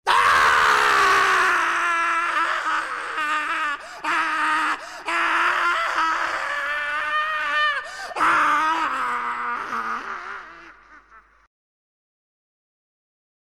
Un brano breve e denso di pathos, riflettente una situazione drammatica ma in cui si intravede una luce di speranza: e' vero che siamo nati per soffrire, ed e' vero pure che ci riusciamo benissimo...